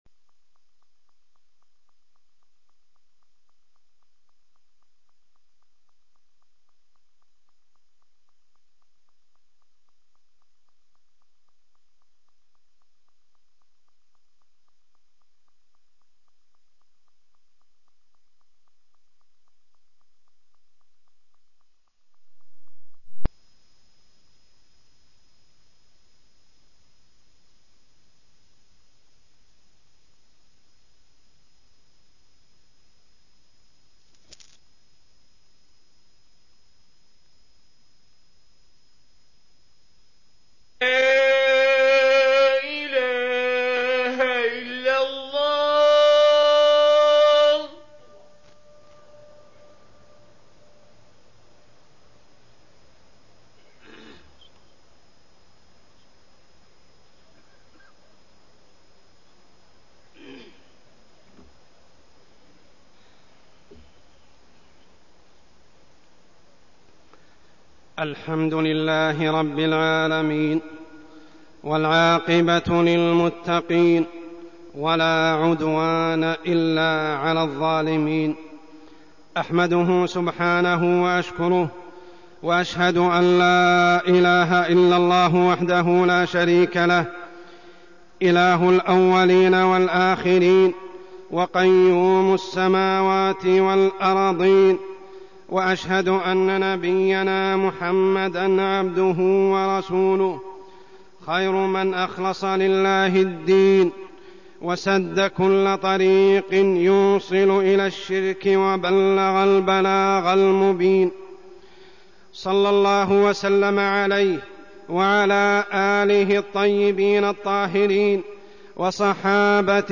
تاريخ النشر ٣٠ جمادى الآخرة ١٤١٨ هـ المكان: المسجد الحرام الشيخ: عمر السبيل عمر السبيل فضل التوحيد